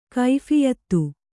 ♪ kaiphiyattu